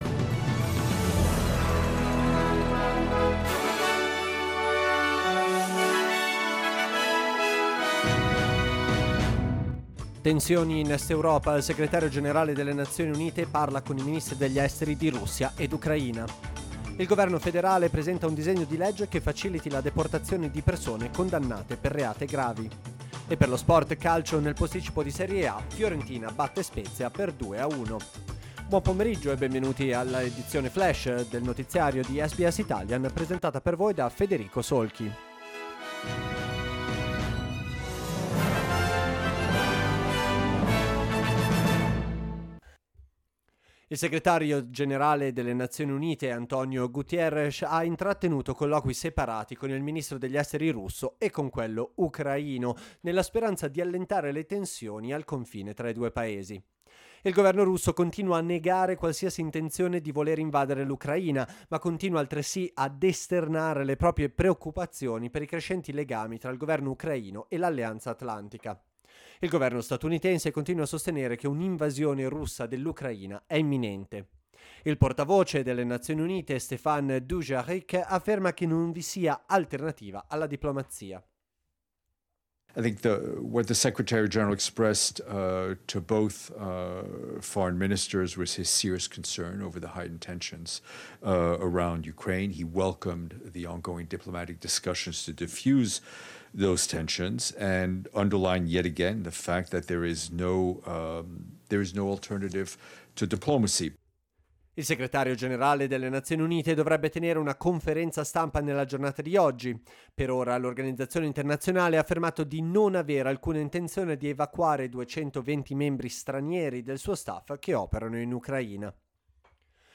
L'aggiornamento delle notizie di SBS Italian.